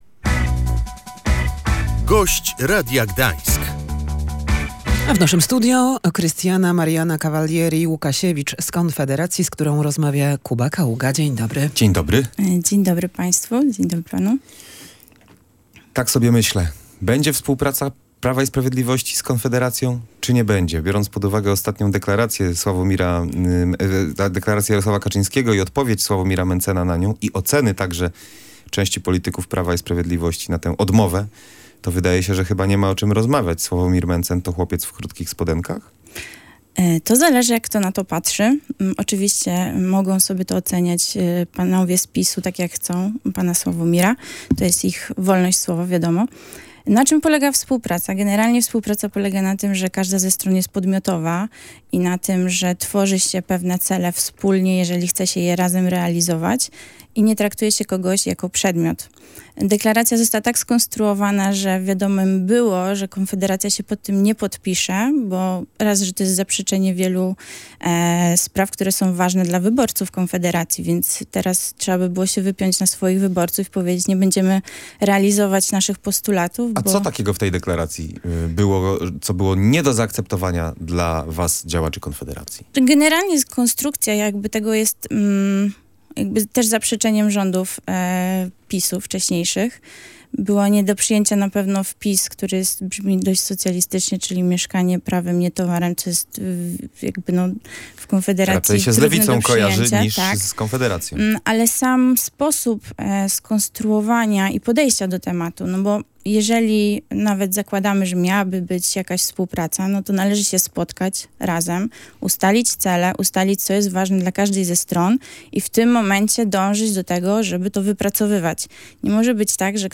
"Deklaracja Polska" zaproponowana przez Prawo i Sprawiedliwość jest nie do przyjęcia dla Konfederacji - mówiła w Radiu Gdańsk członkini partii